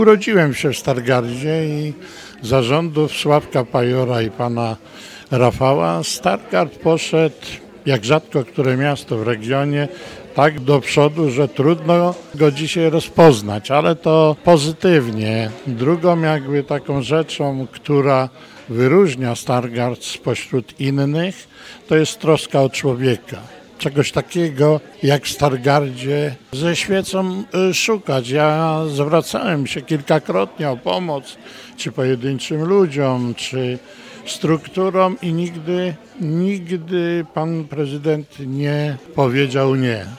W Stargardzkim Centrum Kultury odbyła się konwencja komitetu wyborczego obecnego prezydenta miasta, Rafała Zająca.